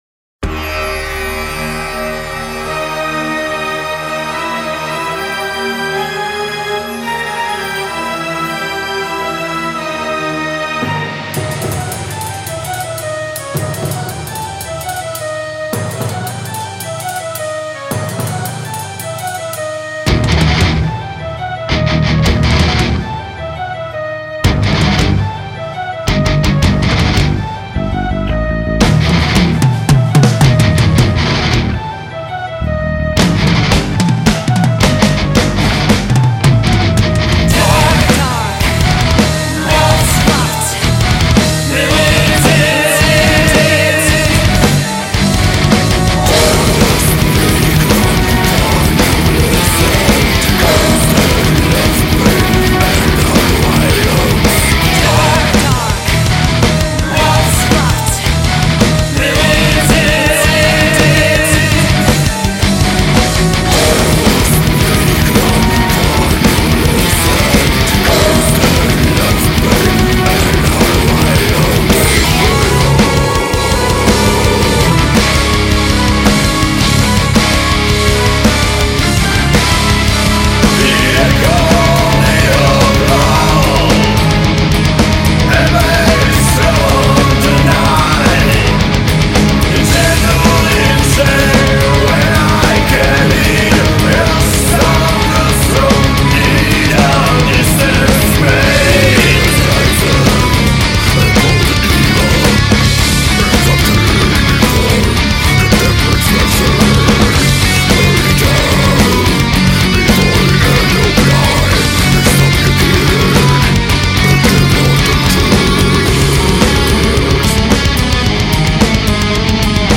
black/death/doom